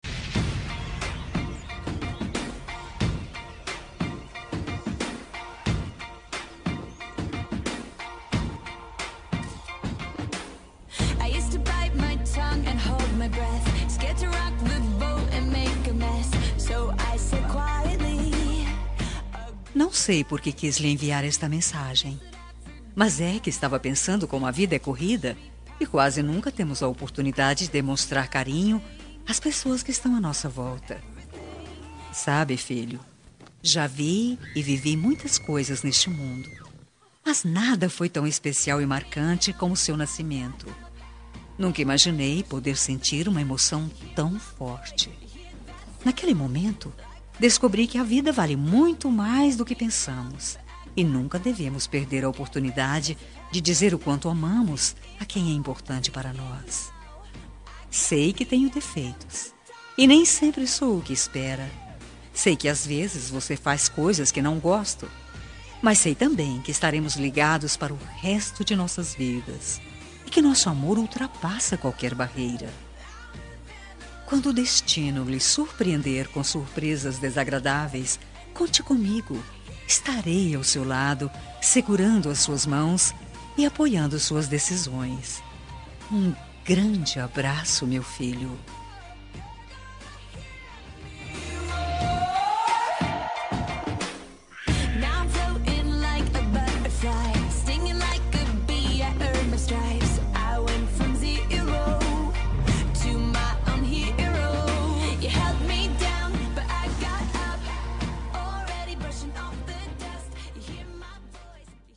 Homenagem para Filho – Voz Feminina – Cód: 8130